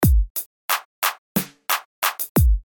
描述：一个基本的循环......踢腿、拍子、嗨帽、小鼓
Tag: 90 bpm Hip Hop Loops Drum Loops 460.76 KB wav Key : Unknown